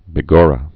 (bĭ-gôrə, -gŏrə)